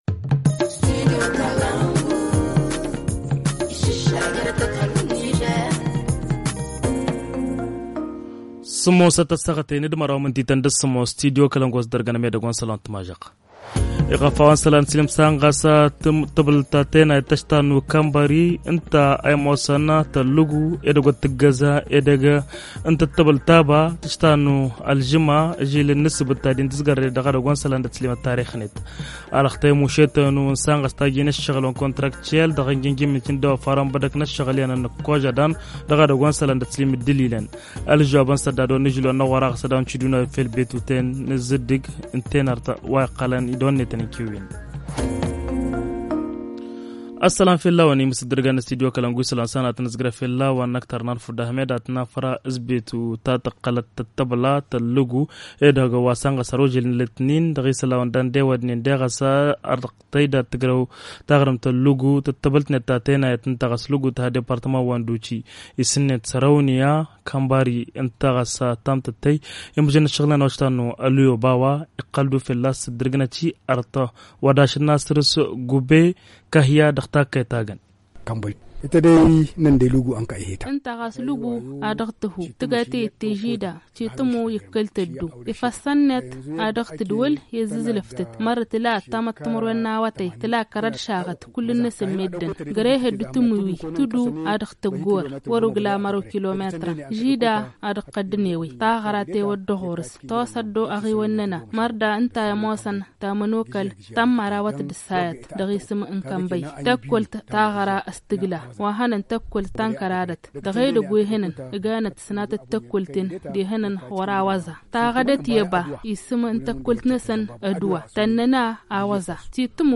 Le journal du 11 janvier 2023 - Studio Kalangou - Au rythme du Niger